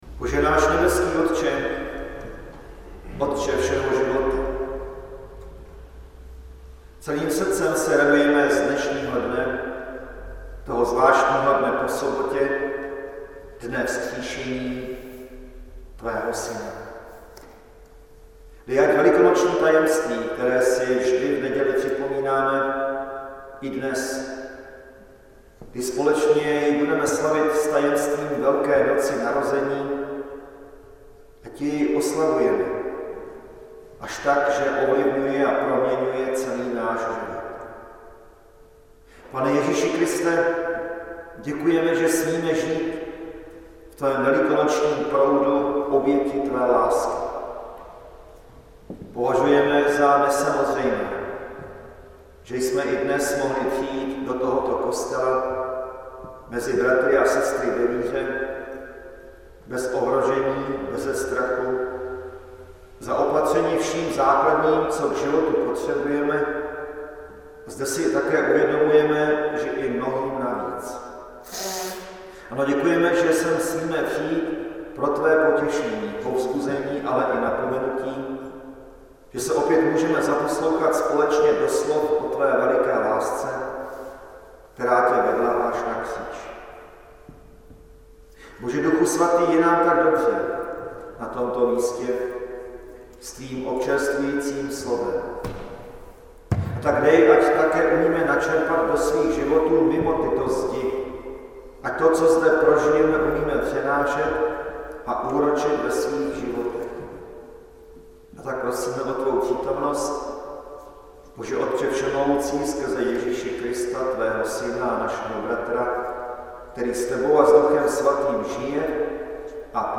Rodinná neděle
záznam kázání